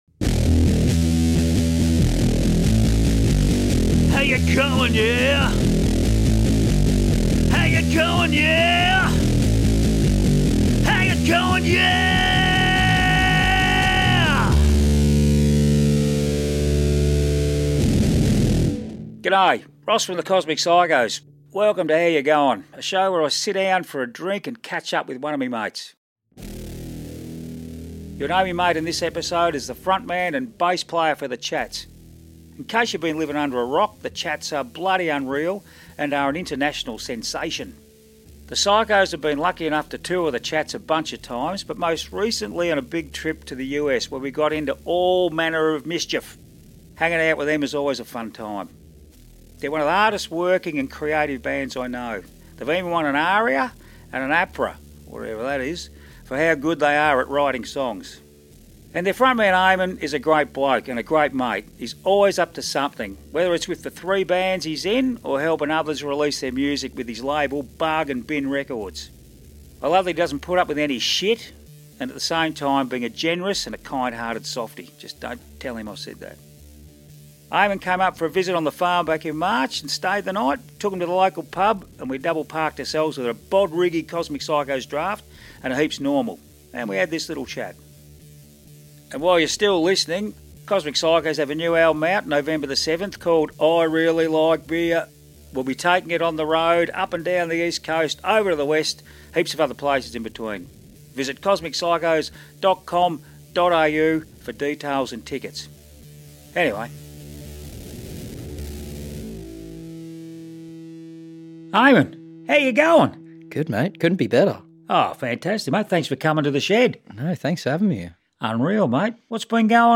We got ourselves a couple of cans, sat down in the shed, and had this chat.